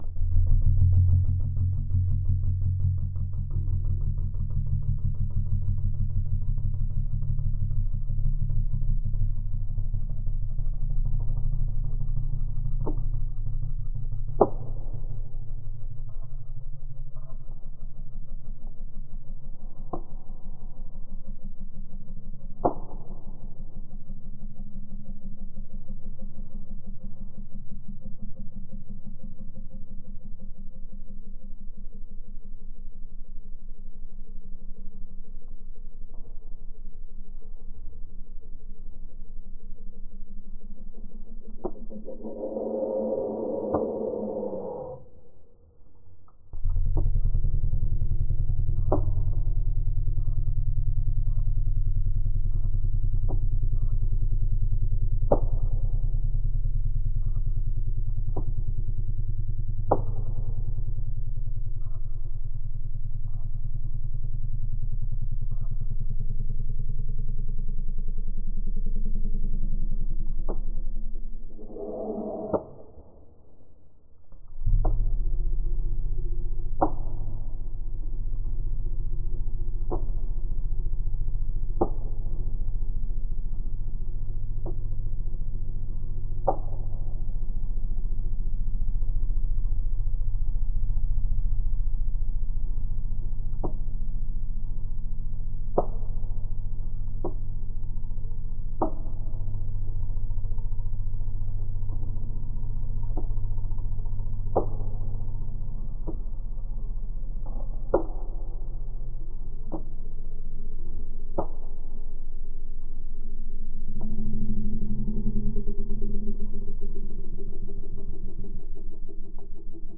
描述：无人机的声音是激烈和可怕的。超深低音。